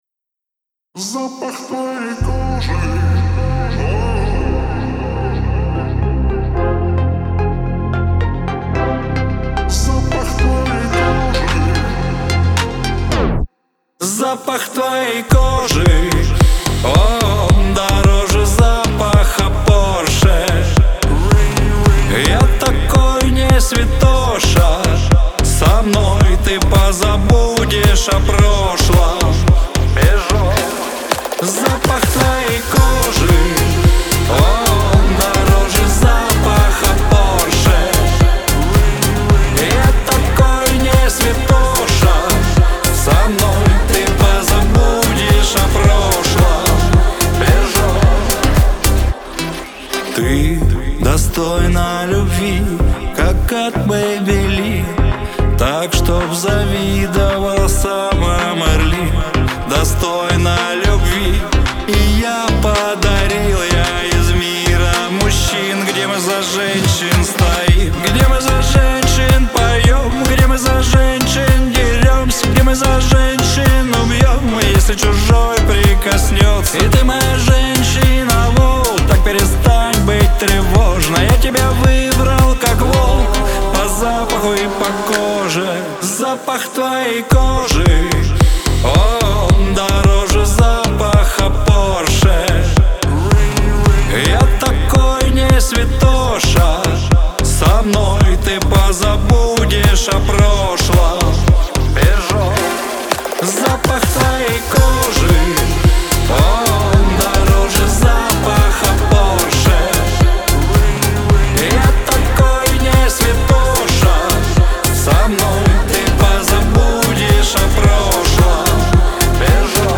грусть
Лирика, Шансон